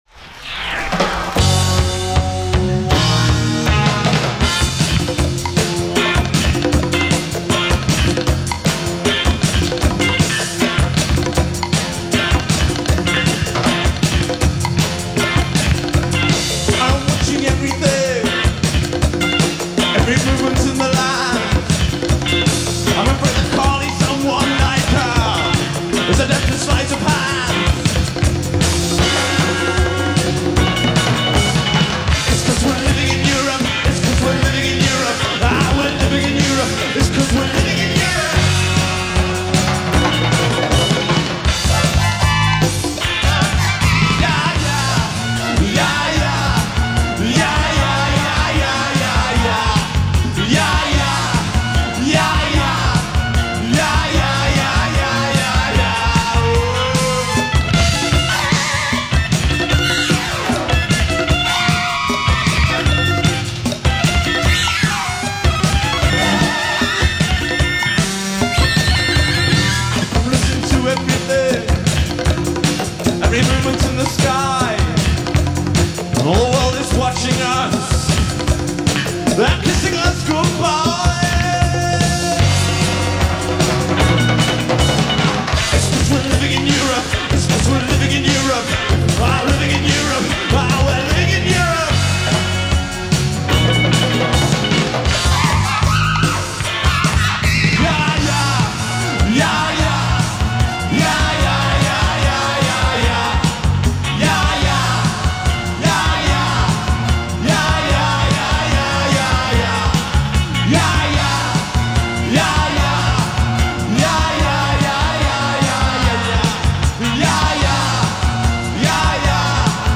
Live at York University